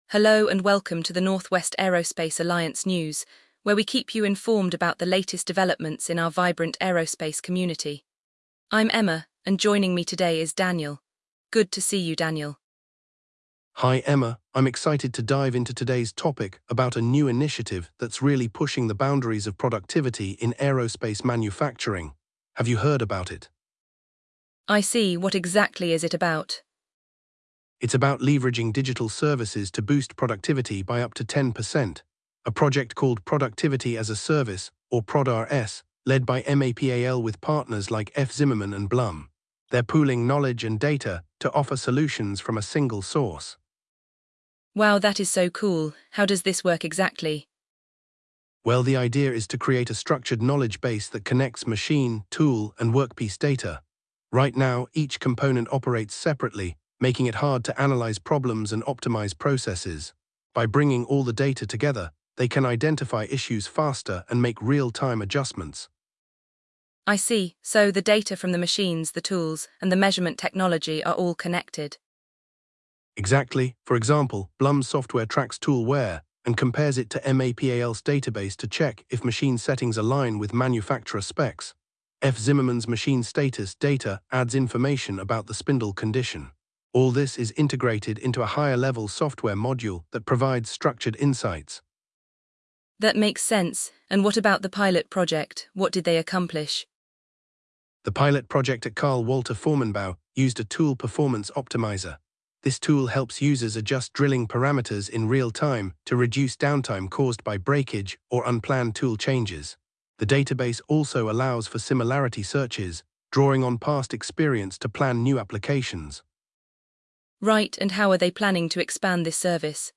Learn how integrating machine, tool, and workpiece data is transforming aerospace manufacturing, enabling real-time optimizations and reducing downtime. The conversation covers the pilot project at Karl Walter Formenbau, the introduction of a traffic light system for immediate alerts, and future developments toward self-learning services and end-to-end automation by 2029.